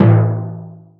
Destroy - Perc Timpani.wav